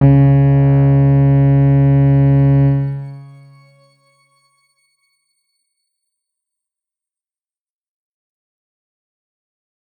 X_Grain-C#2-pp.wav